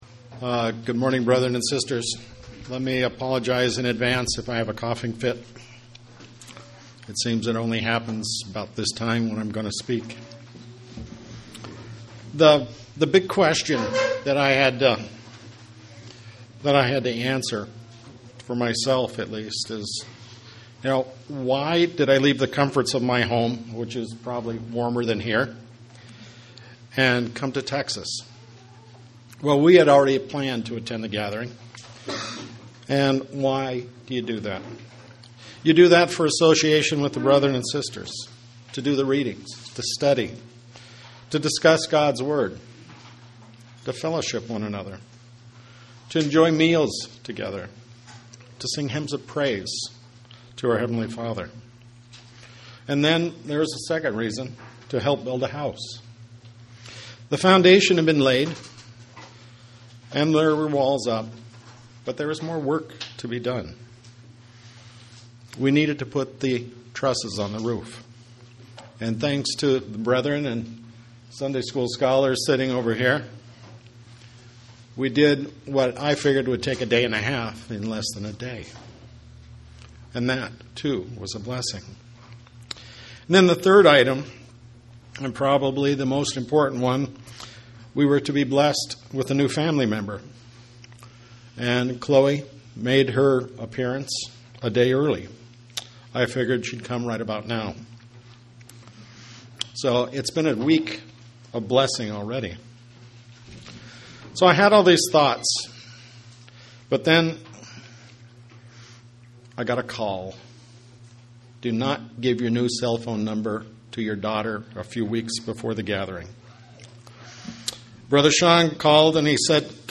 The recordings of the talks from the 2015 Goldthwaite Gathering are now available for downloading.